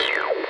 radiobutton_unchecked.wav